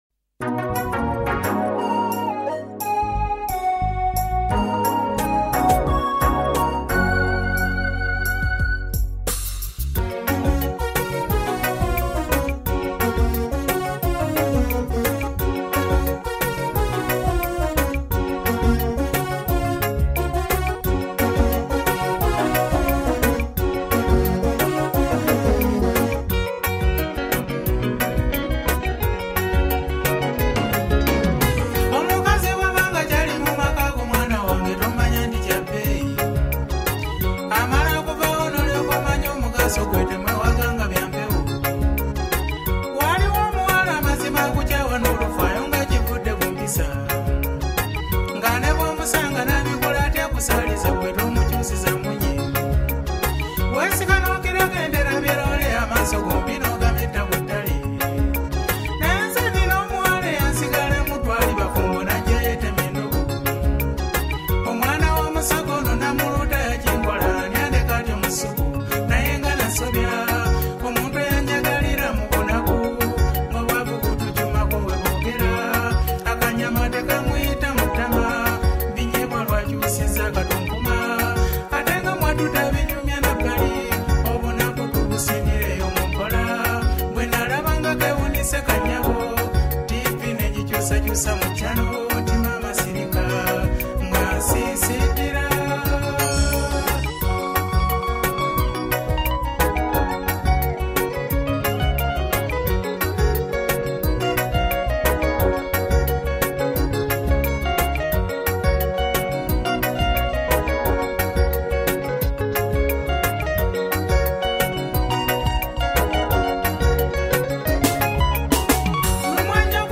Genre: Kadongo Kamu